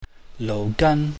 /nouh/ \gan\